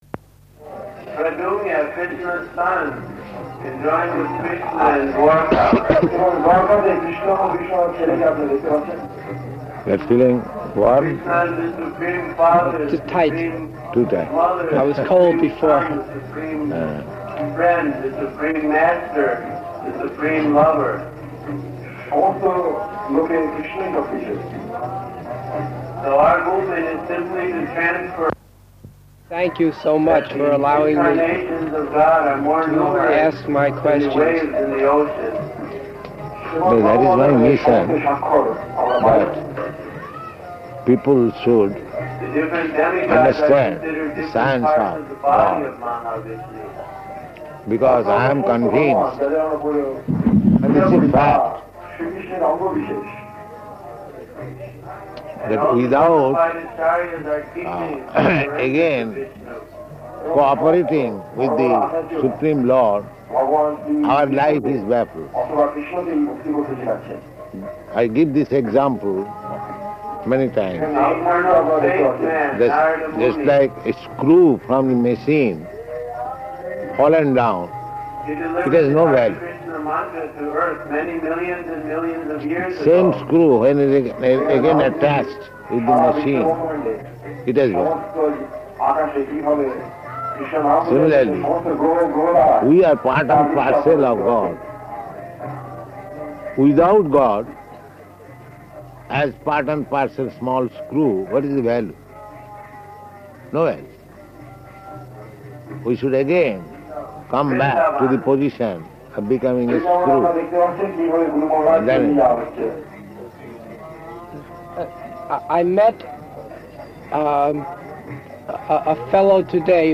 Type: Conversation
Location: Māyāpur